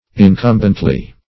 incumbently - definition of incumbently - synonyms, pronunciation, spelling from Free Dictionary Search Result for " incumbently" : The Collaborative International Dictionary of English v.0.48: Incumbently \In*cum"bent*ly\, adv.
incumbently.mp3